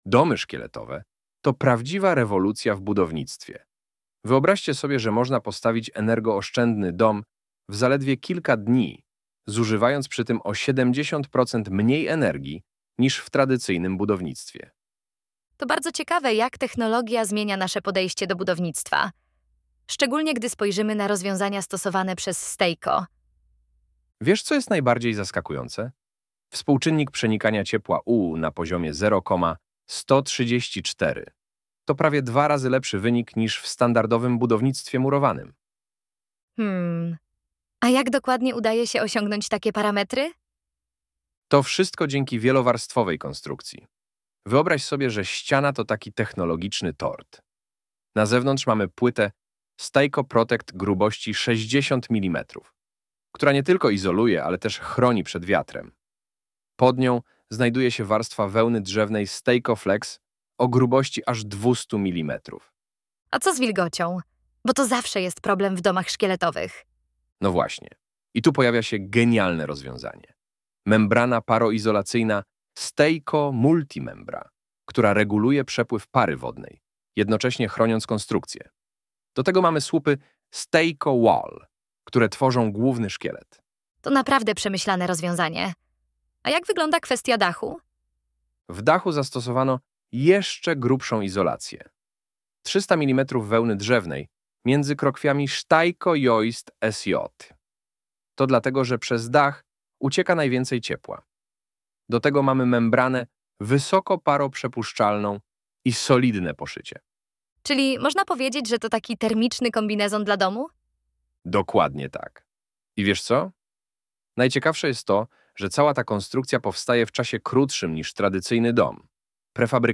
Poznajcie naszych wirtualnych inżynierów Chrisa i Jessicę , którzy opowiedzą wam wiele ciekawych historii w poniższych podcastach 😃